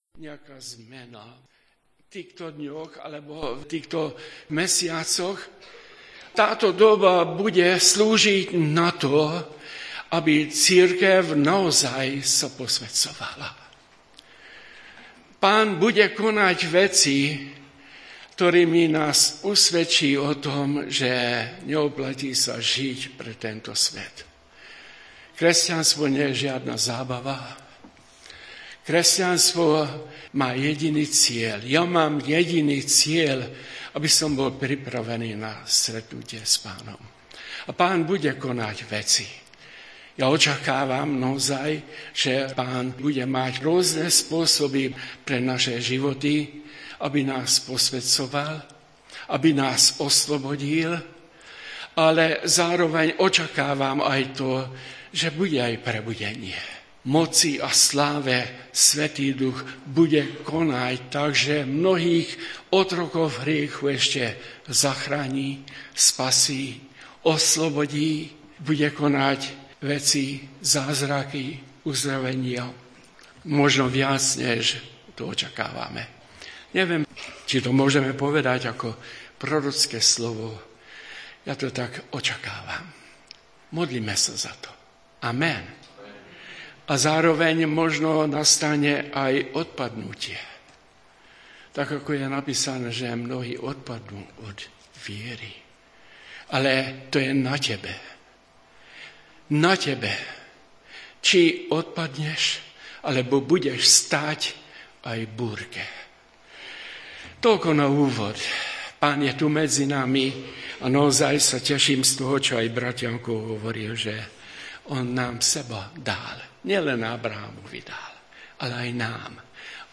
Vypočujte si kázne z našich Bohoslužieb